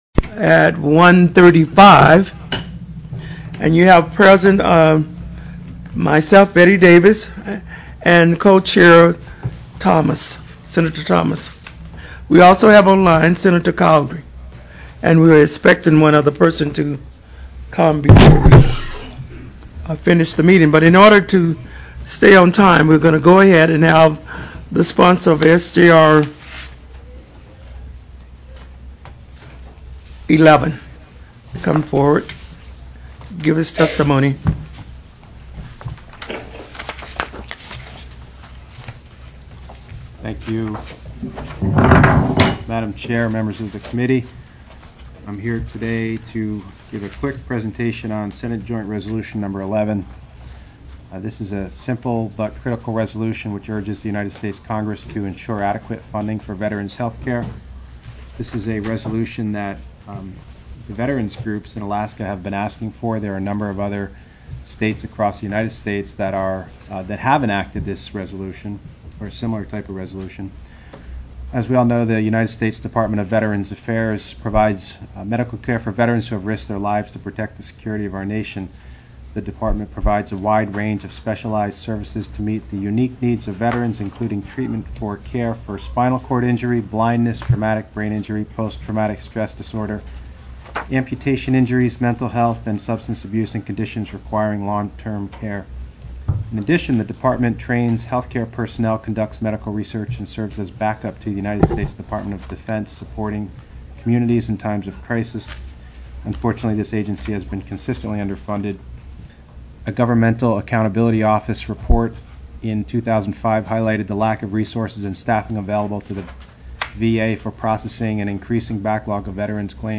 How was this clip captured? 02/04/2008 01:30 PM Senate HEALTH, EDUCATION & SOCIAL SERVICES